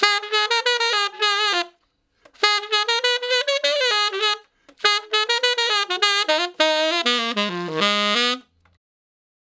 068 Ten Sax Straight (Ab) 12.wav